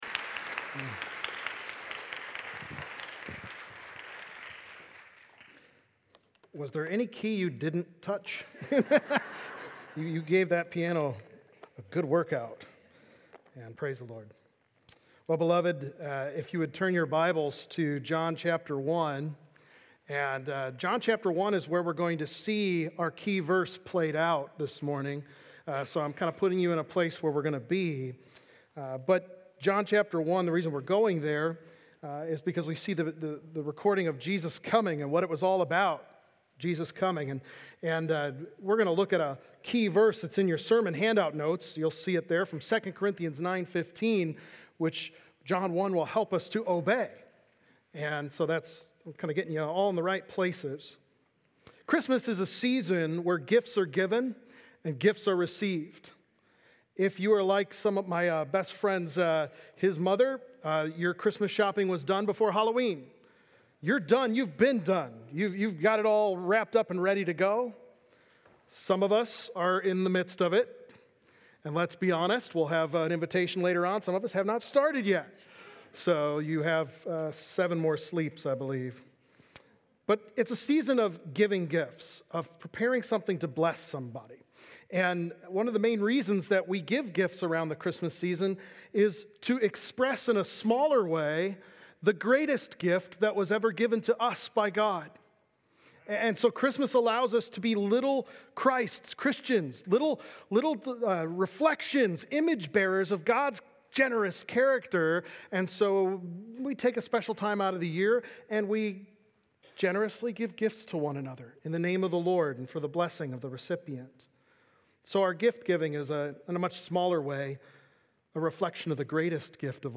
Christmas Blessings | Baptist Church in Jamestown, Ohio, dedicated to a spirit of unity, prayer, and spiritual growth